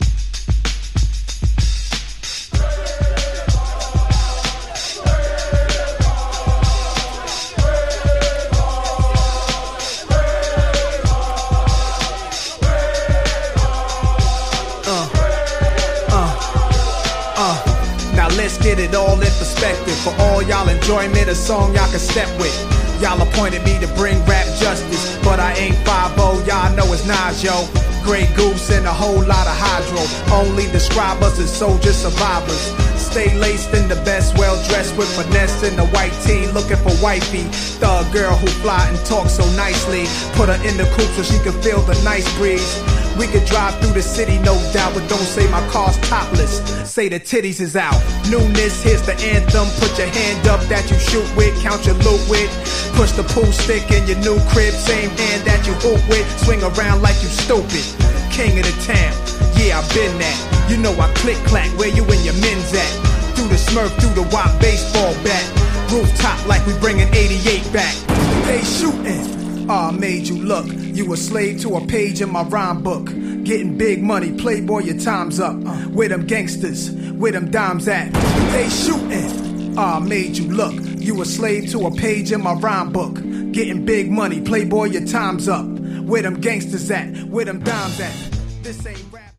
95 bpm Dirty Version Duration